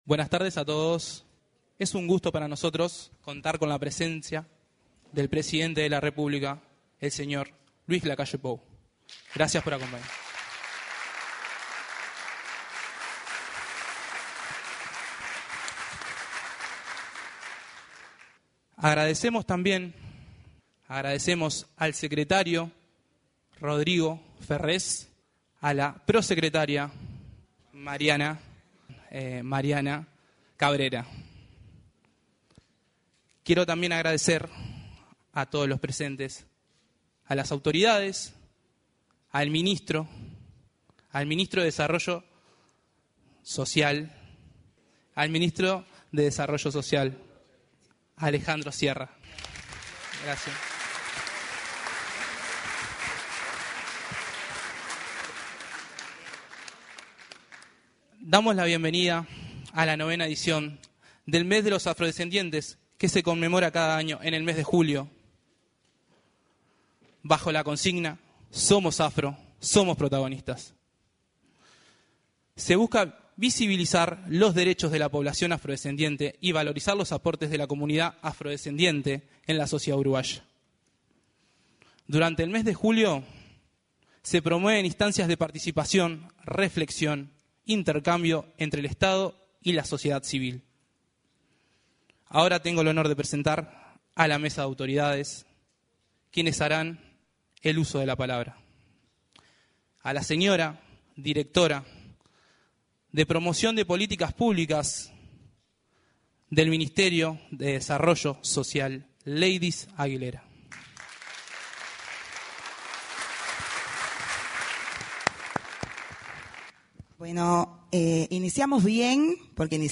Este viernes 5, en la Torre Ejecutiva, se realizó el lanzamiento del Mes de la Afrodescendencia, ceremonia en la que se expresaron la directora de